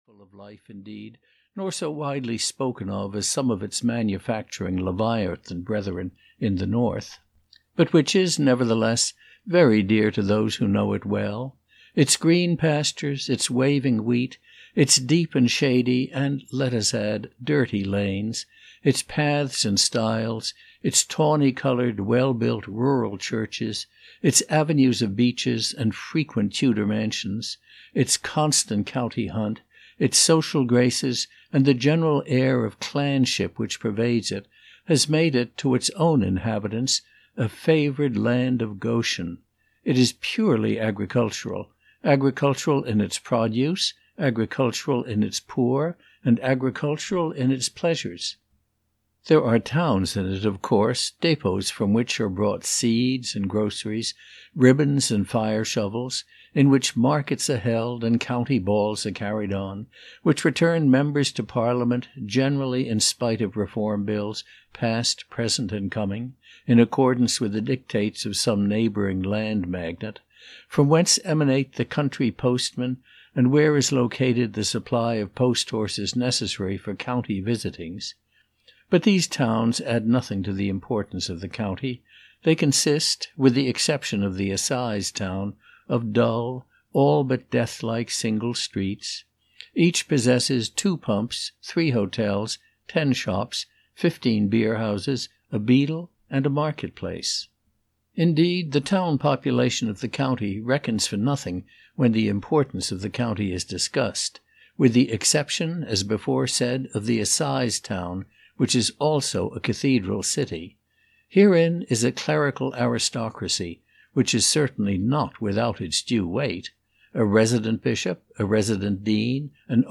Doctor Thorne (EN) audiokniha
Ukázka z knihy